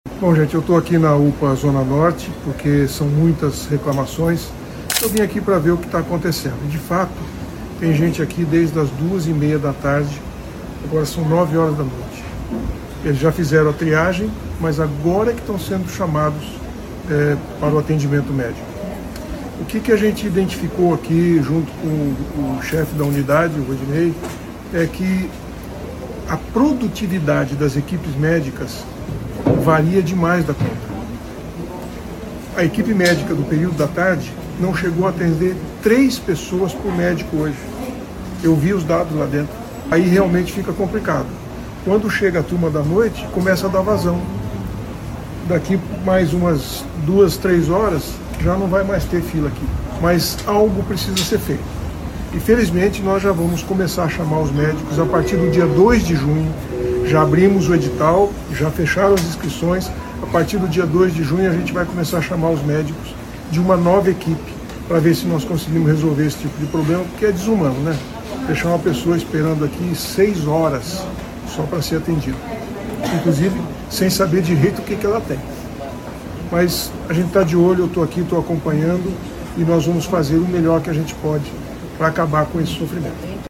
Ouça o relato do prefeito Sivio Barros nas redes sociais: